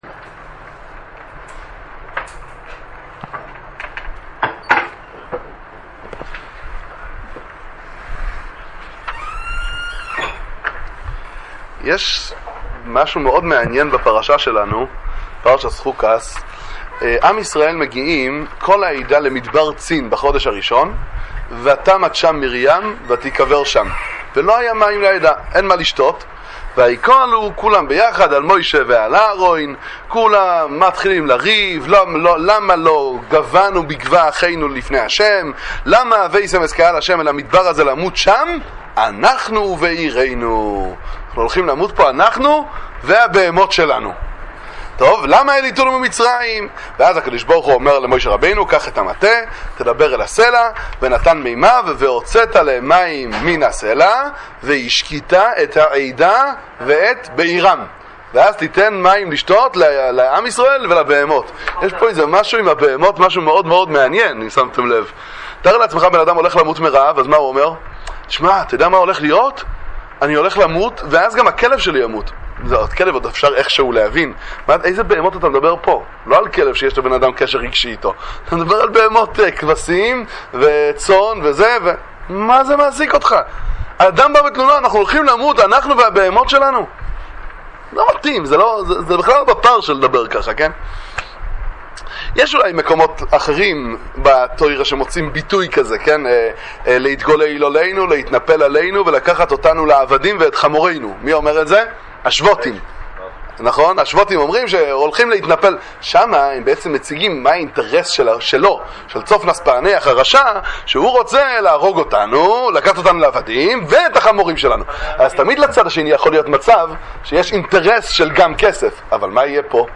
דבר תורה קצר לשמיעה על פרשת השבוע מהספר זרע שמשון